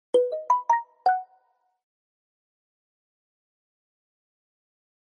Samsung S20 Notification Sound Effect Free Download
Samsung S20 Notification